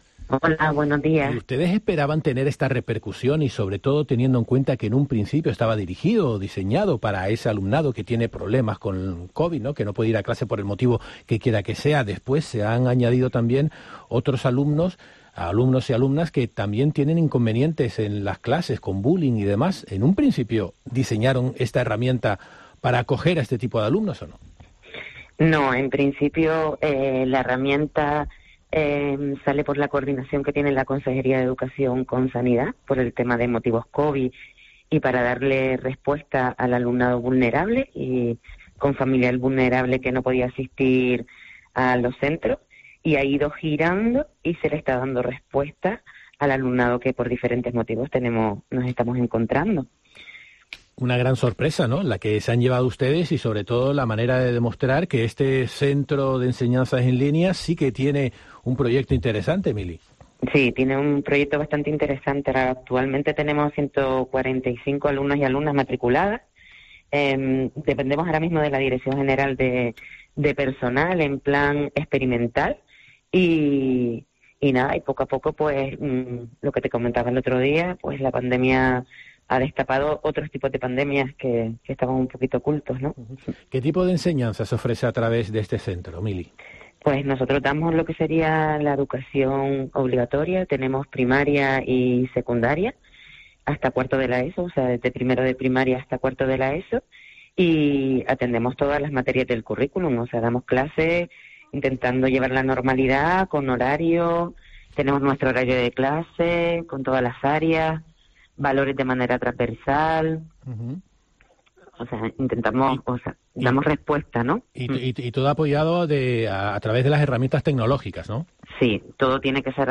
pasó hoy por La Mañana de COPE Canarias y confirmaba que el proyecto ha ido más allá, ya que no